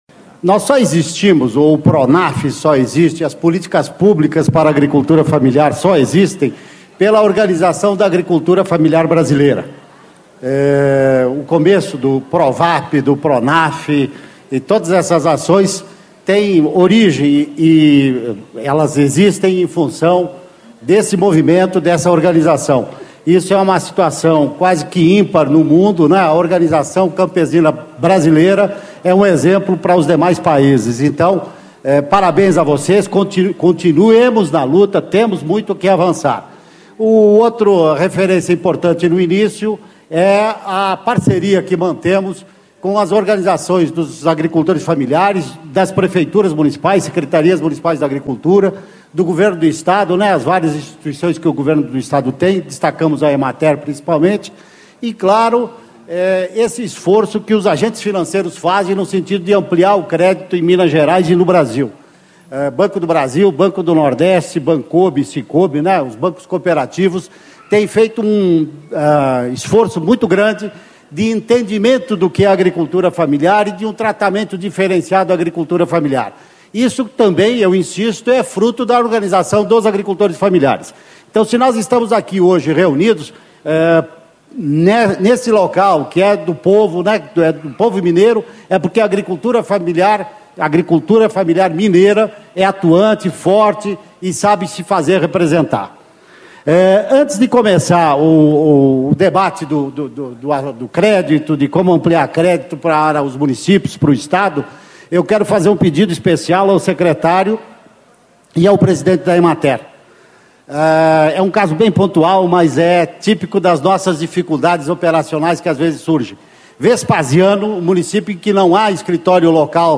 João Luiz Guadagnin, Diretor do Departamento de Financiamento e Proteção da Produção da Secretaria de Agricultura Familiar do Ministério do Desenvolvimento Agrário - Painel: Financiamento e Crédito Rural
Discursos e Palestras